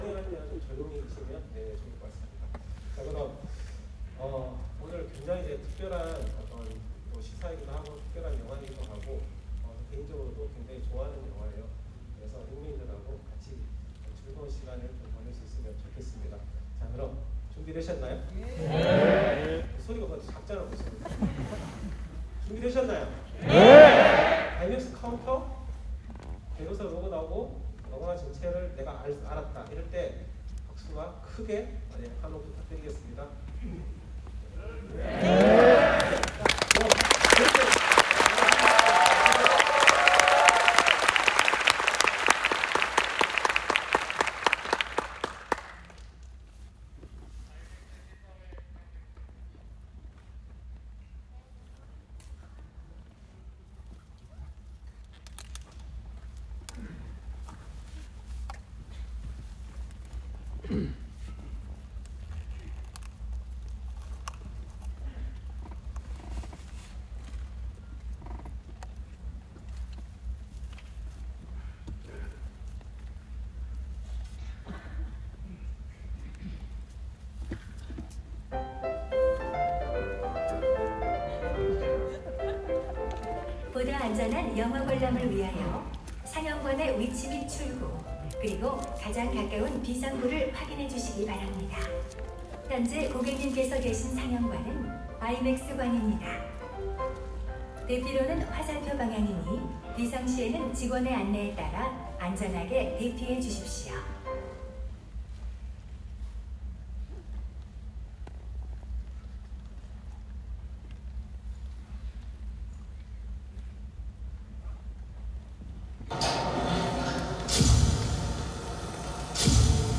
* 분위기가 너무 좋아서 기록해놓고 싶어서 그부분만 녹음했었는데 이제 올려봅니다.
당시 현장 분위기는 화기애애하고 훈훈하고 열기넘치고 여튼 너무 좋았습니다.
카운트다운 맞추기 진짜 쉽지 않더군요 ㅎㅎ
0:45-1:55 블랭크, 안전안내
1:55-2:43. 아맥 카운트다운
2:50-3:22 제작사로고 & 영화시작